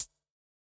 Closed Hats
hihat (Wtf).wav